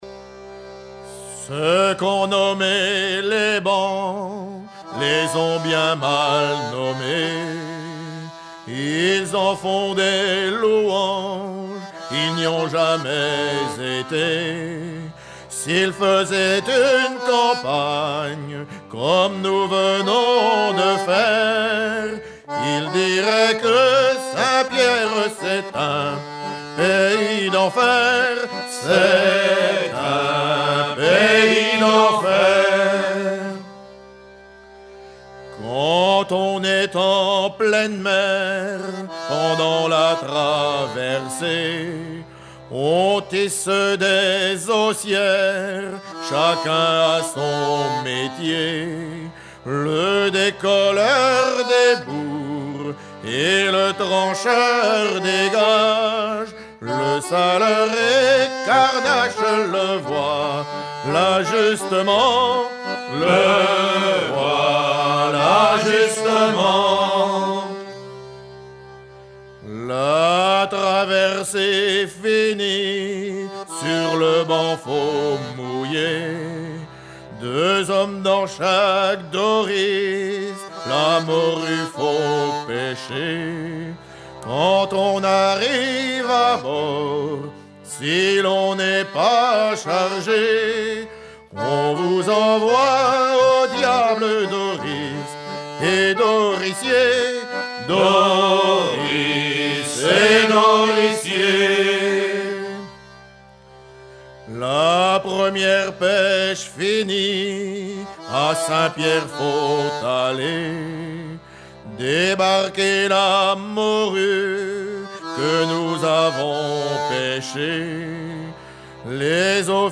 Recueilli en 1976 auprès du terre-neuvas
Pièce musicale éditée